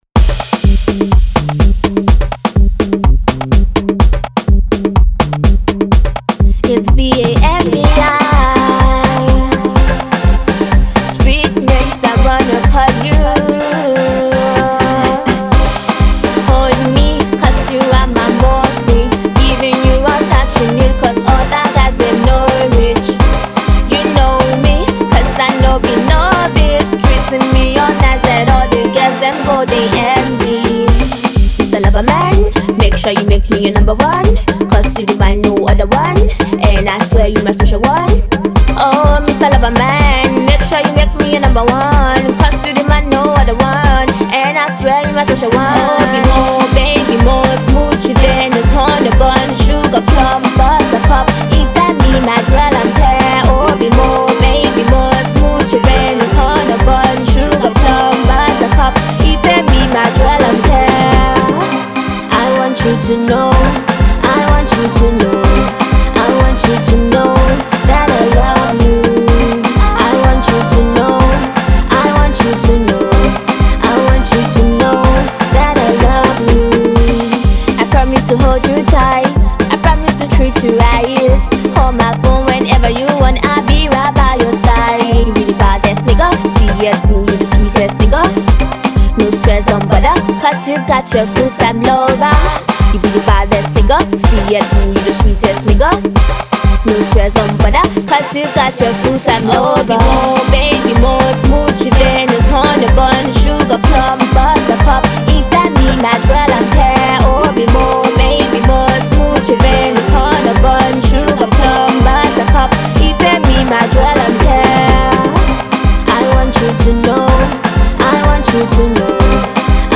it’s a love song that you would want to listen to.